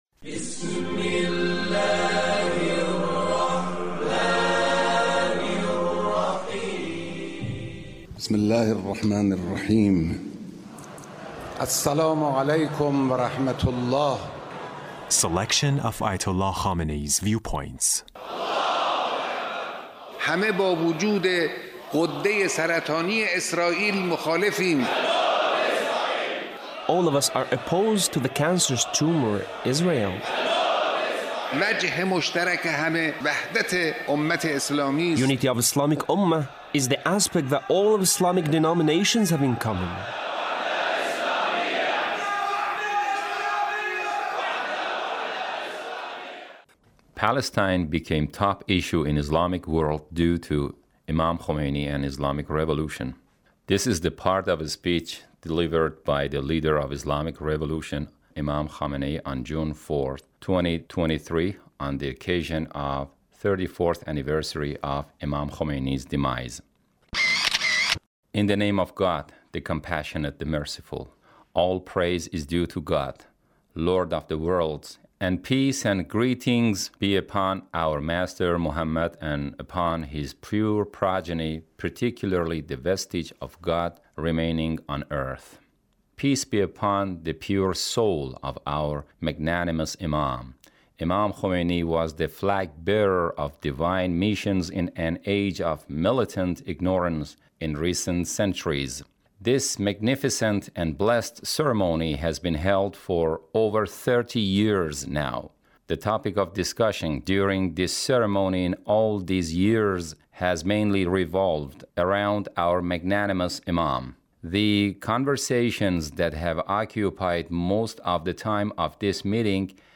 Leader's Speech (1733)
Leader's Speech on the occasion of the 34th anniversary of Imam Khomeini’s demise. 2023